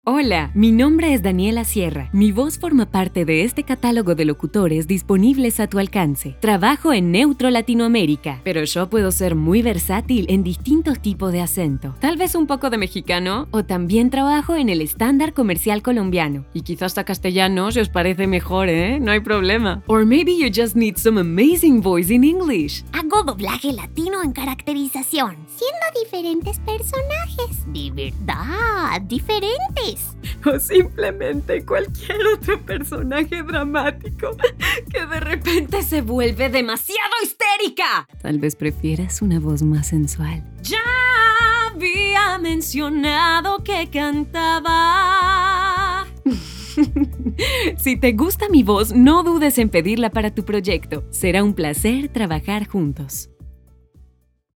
VOICE ACTRESS - VOICEOVER - SINGER Hi!
- Microphone: Neumann TLM 102 - Computer & Software: imac 21" / Logic Pro X - Special Equipment: Acustically trated room - Delivery Methods: All digital formats available - Turnaround Time: 10 hours for most projects
Sprechprobe: Werbung (Muttersprache):